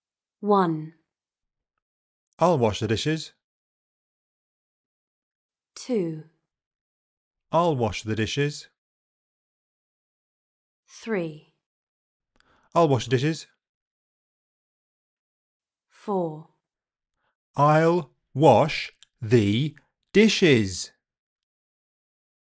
The word THE has over 10 pronunciations in British English, it all depends on surrounding sounds and speed of speech.